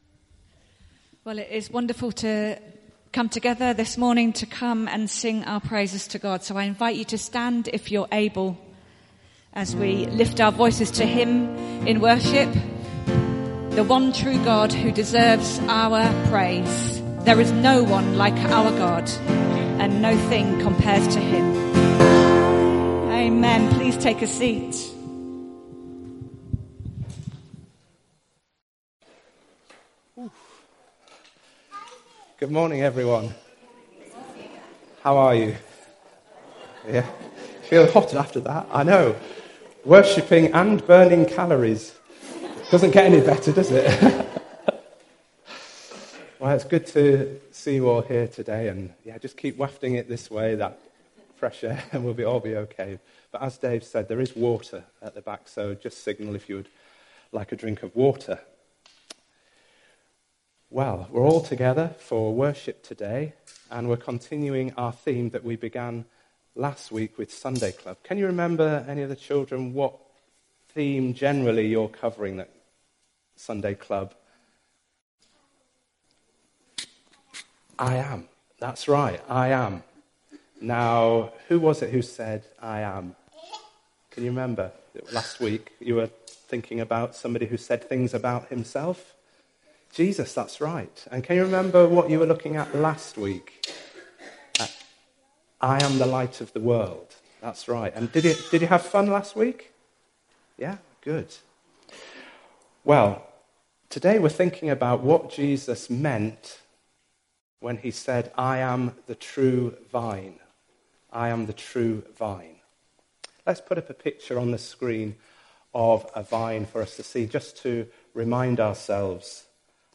A service of morning worship for all ages.